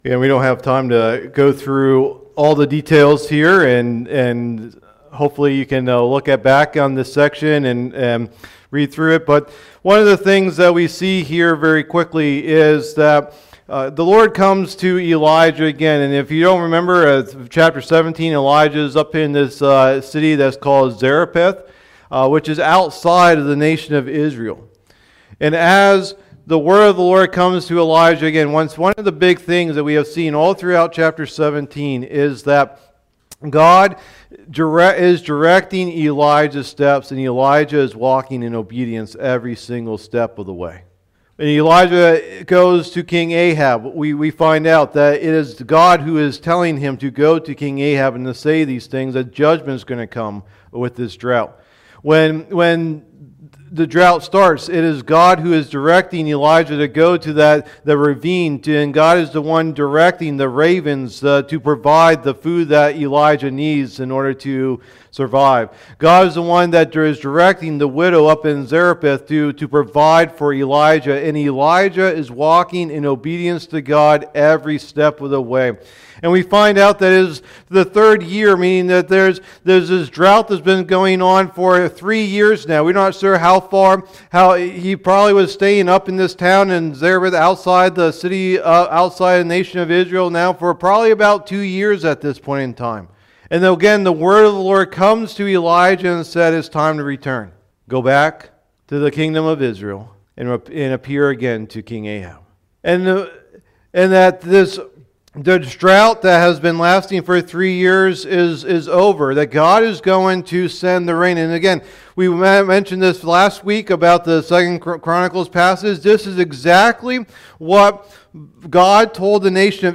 Message #5 of the "Life of Elijah" teaching series